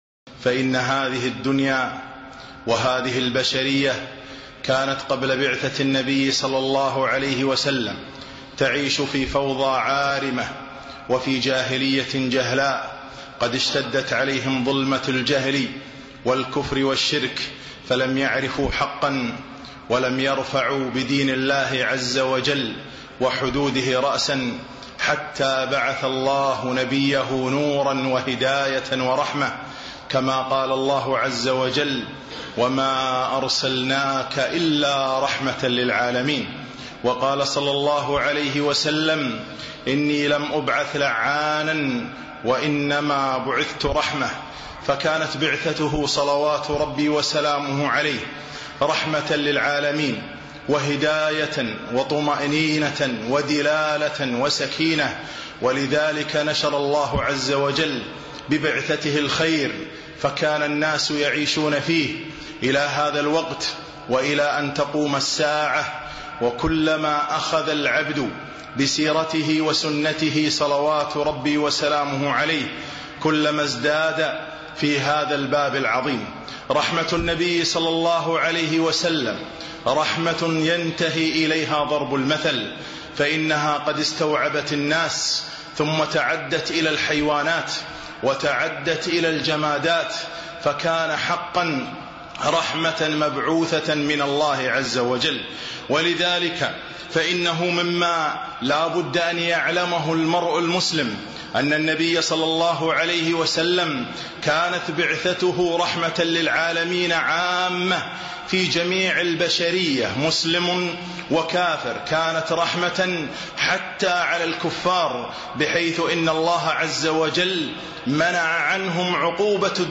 خطبة - رحمة النبي صلى الله عليه وسلم